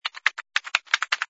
sfx_keyboard_flurry01.wav